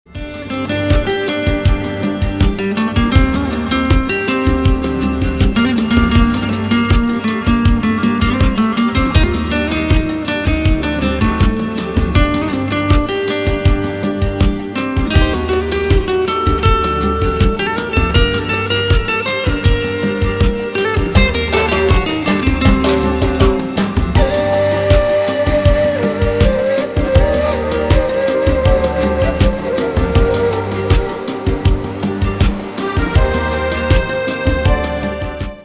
• Качество: 320, Stereo
гитара
спокойные
без слов
chillout
инструментальные
медленные
Lounge
New Age